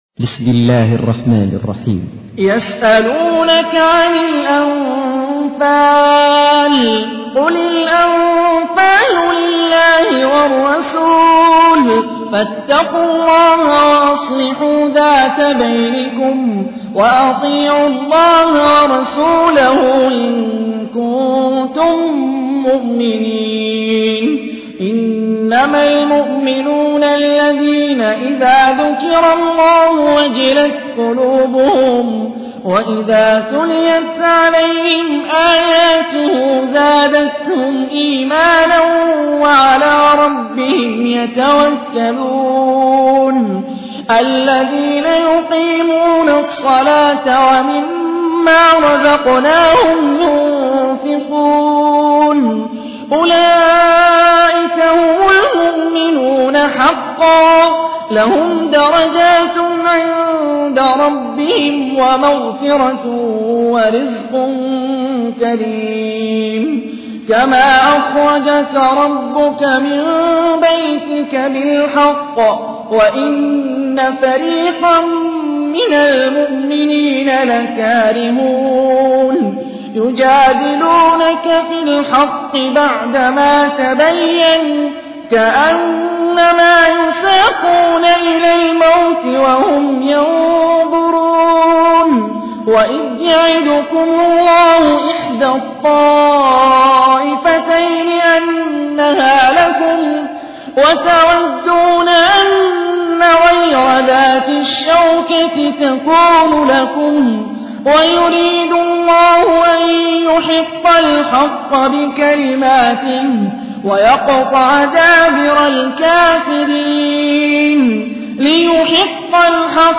تحميل : 8. سورة الأنفال / القارئ هاني الرفاعي / القرآن الكريم / موقع يا حسين